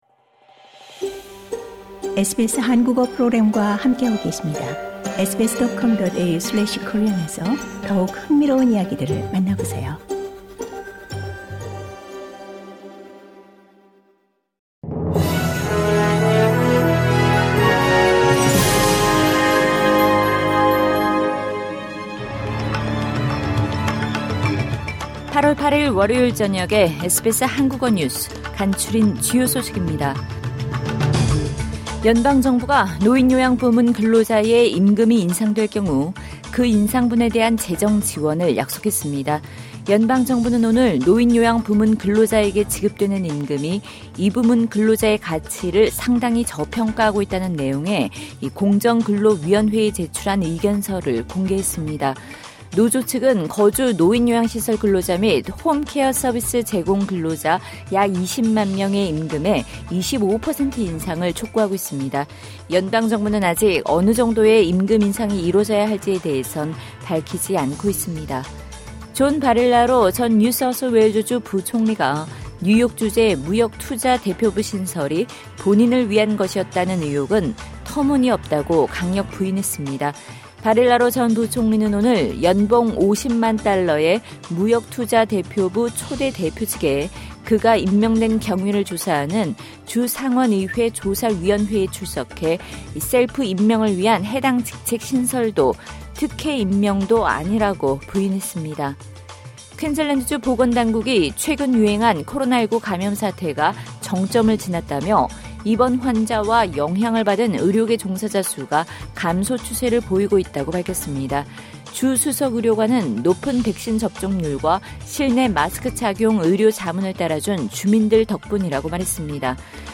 SBS 한국어 저녁 뉴스: 2022년 8월 8일 월요일
2022년 8월 8일 월요일 저녁 SBS 한국어 간추린 주요 뉴스입니다.